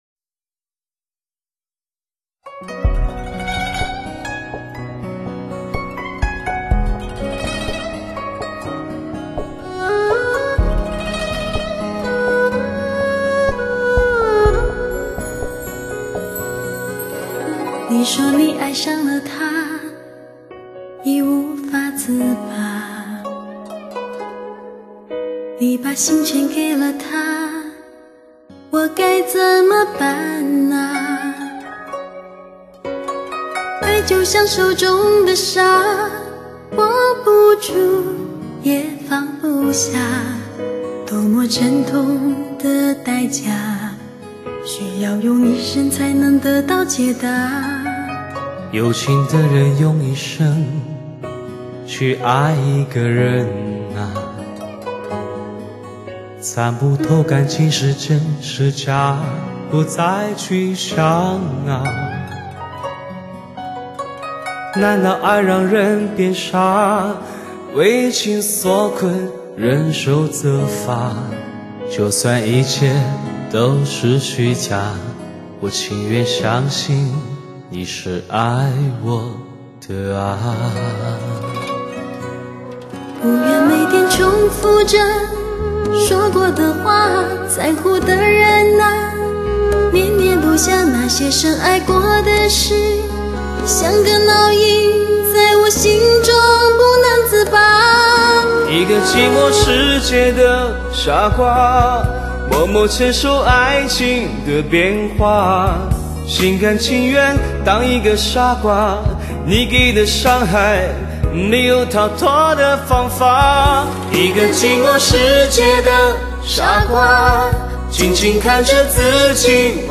经典与流行融入发烧，最畅销靓声
呵呵，好动听的对唱
伤感对唱.